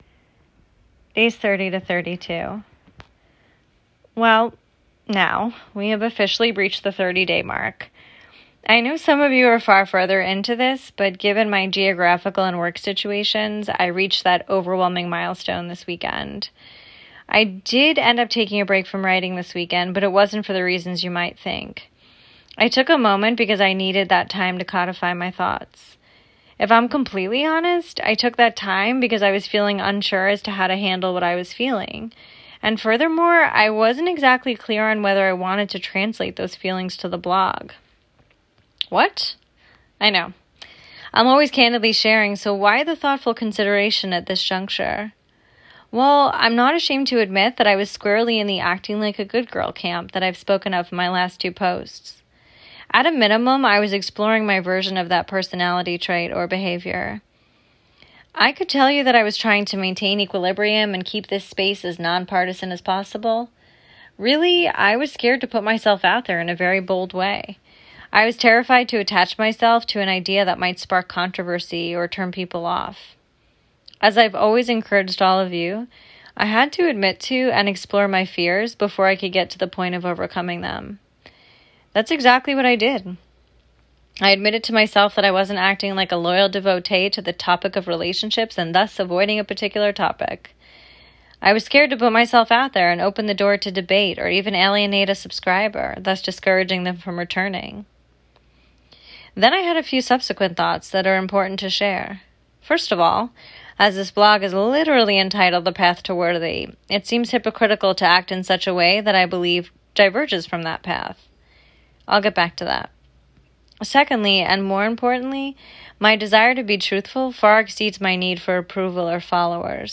Note: I was a little flustered during this recording because I feel SO strongly about this topic.